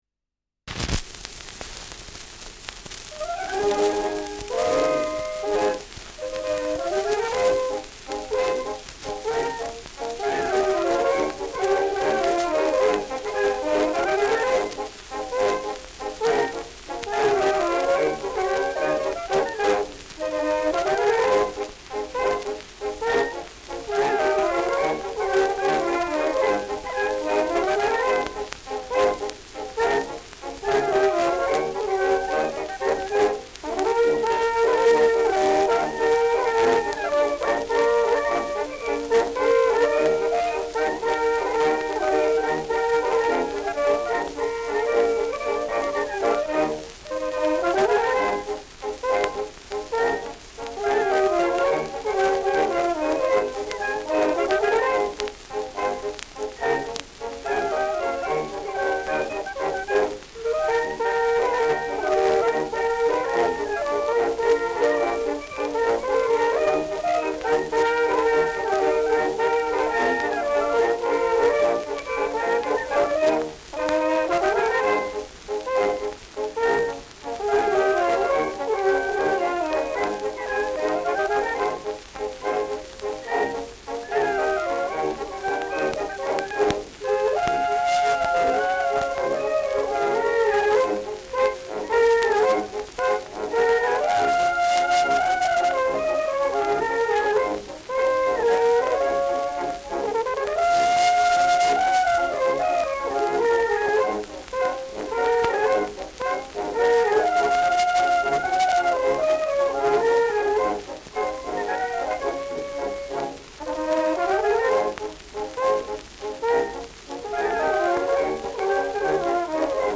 Schellackplatte
präsentes Rauschen
Stadtkapelle Fürth (Interpretation)